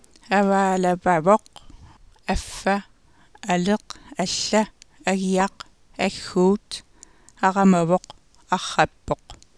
[ævæ:lævævɒq æf:æ ælɜq æɬ:æ æɣiɑq æx:u:t ɑʁæmævɒq ɑχ:æp:ɒq]